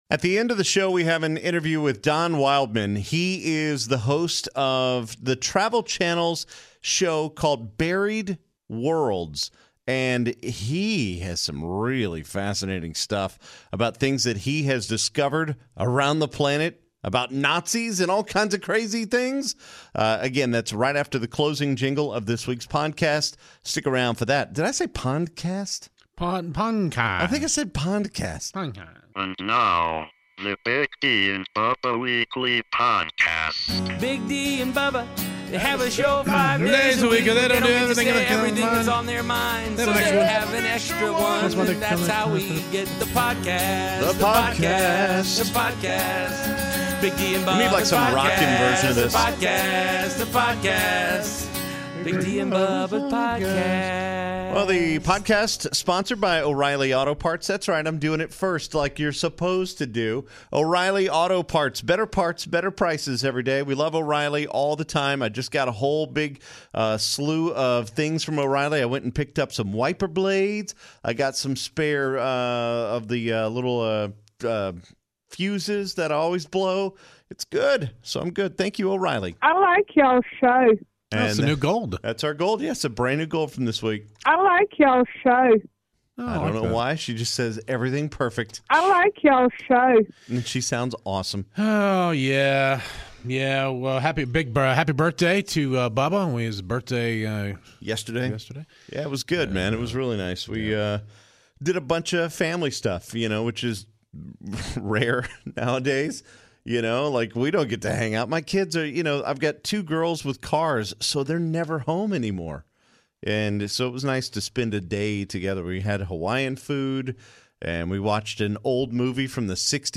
Don Wildman from the Travel Channel show Buried Worlds joins us at the end of Weekly Podcast #299.